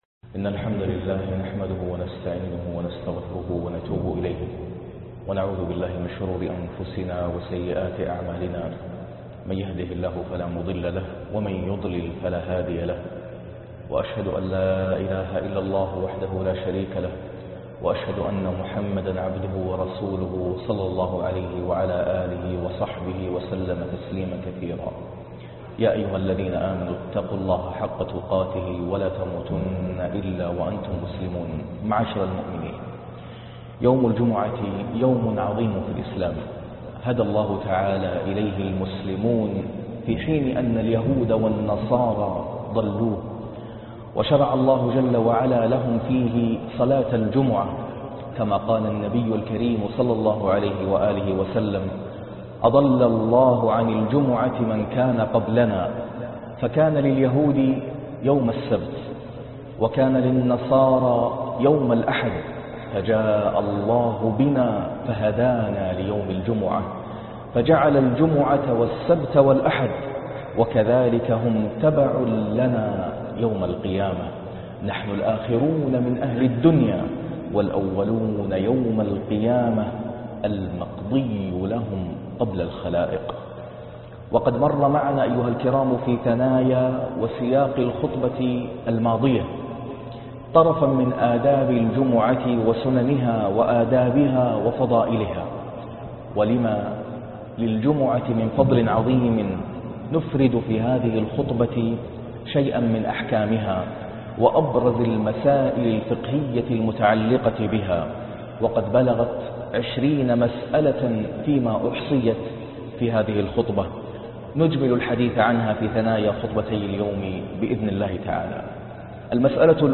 فقه الجمعة " 20 مسألة فقهية في أحكام الجمعة " - خطبة الجمعة " 1 صفر 1437 هـ " - القاريء ناصر القطامي - الطريق إلى الله